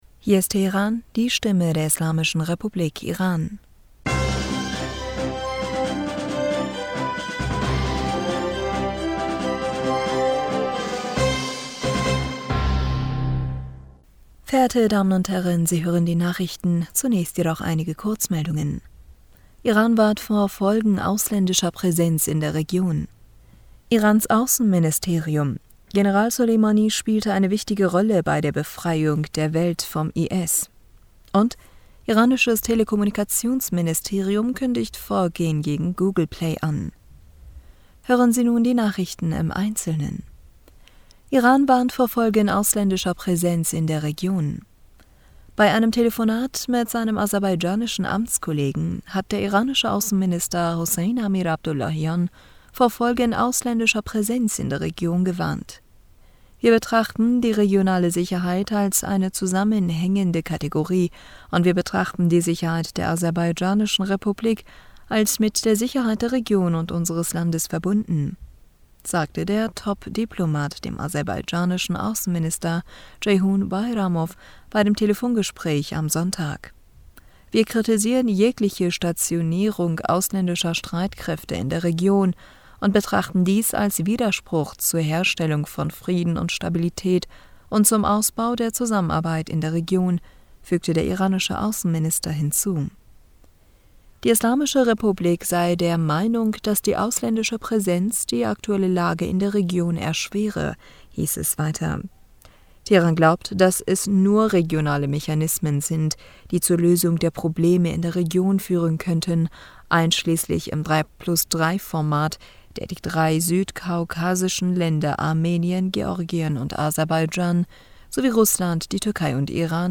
Nachrichten vom 31. Oktober 2022